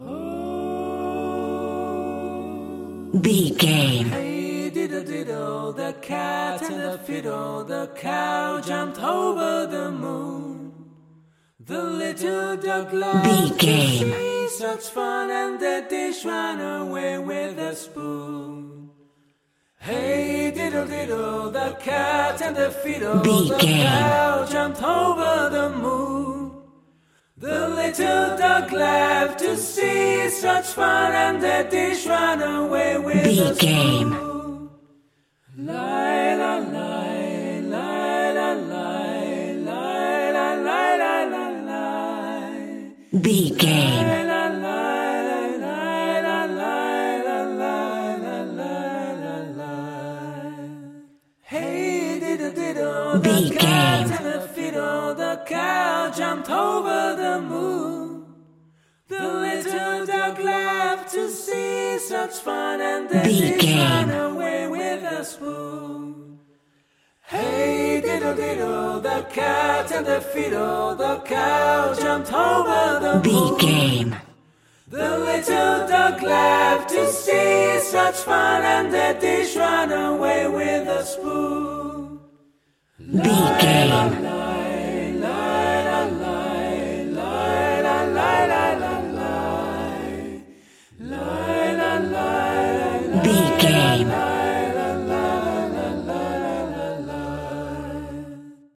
Nursery Rhyme Acapella
royalty free music
Ionian/Major
D
Fast
nursery rhymes
fun
childlike
happy